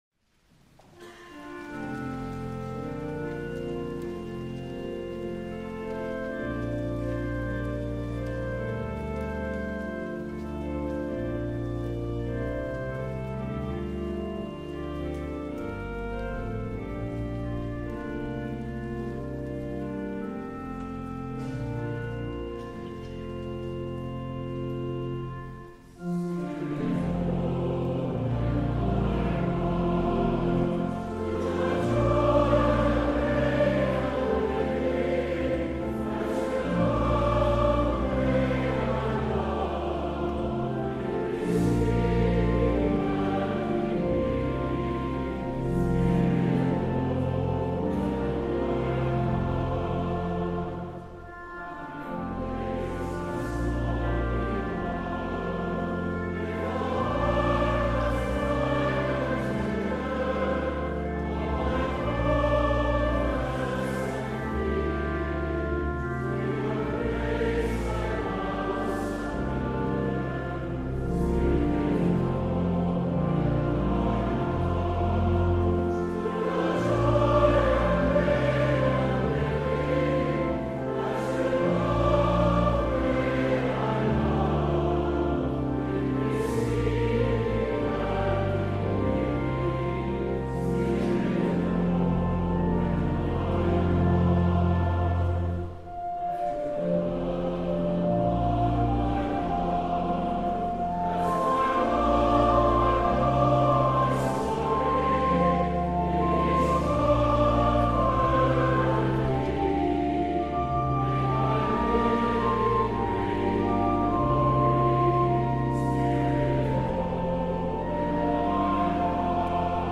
HYMN: Ruth C Duck ©